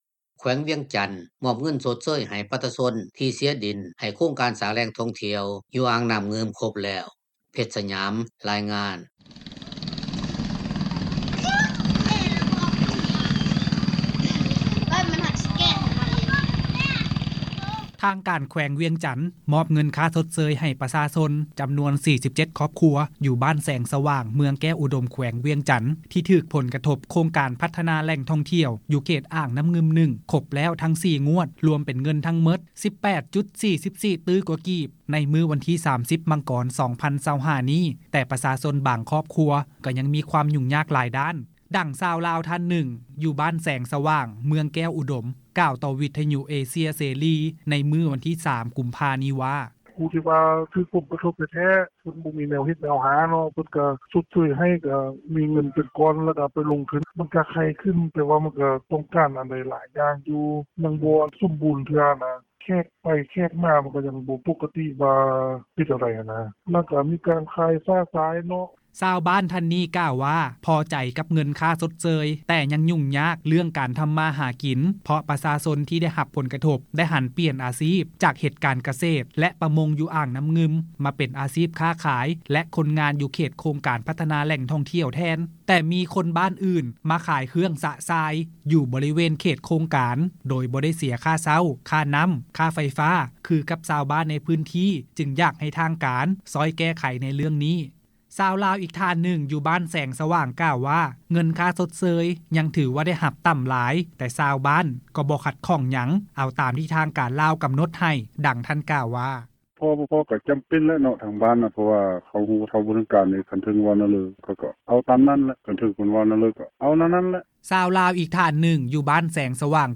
ດັ່ງຊາວລາວທ່ານໜຶ່ງ ຢູ່ບ້ານແສງສະຫວ່າງ ເມືອງແກ້ວອຸດົມ ກ່າວຕໍ່ ວິທຍຸເຊັຽເສຣີ ໃນມື້ວັນທີ 3 ກຸມພາ ນີ້ວ່າ:
ຂາວລາວອີກນາງໜຶ່ງ ຢູ່ບ້ານແສງສະຫວ່າງ ກ່າວວ່າ ພໍໃຈກັບເງິນຄ່າຊົດເຊີຍທີ່ໄດ້ຮັບ ເພາະໂຄງການພັດທະນາແຫຼ່ງທ່ອງທ່ຽວ ຢູ່ເຂດອ່າງນ້ຳງື່ມ 1 ເຮັດໃຫ້ຄຸນນະພາບການເປັນຢູ່ຂອງຊາວບ້ານໃນພື້ນທີ່ ມີຊີວິດທີ່ດີຂຶ້ນ, ດັ່ງຍານາງກ່າວວ່າ: